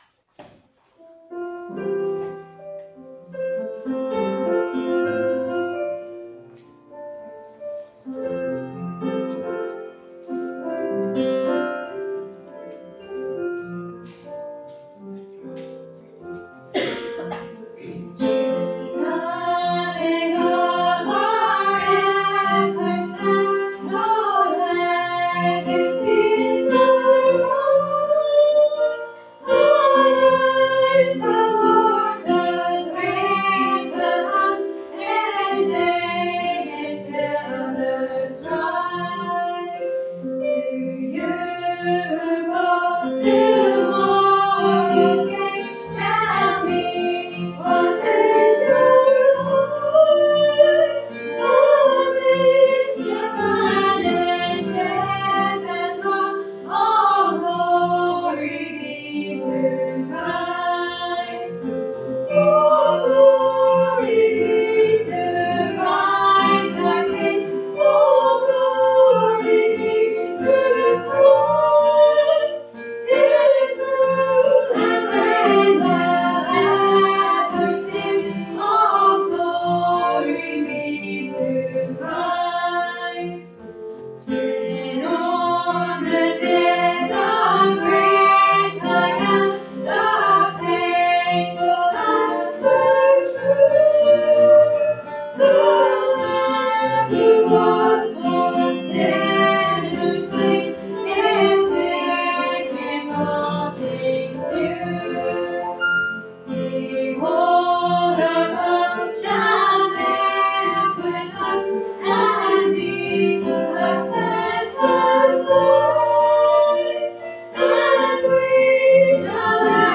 Goście z Angli – pieśń – 16.01.2023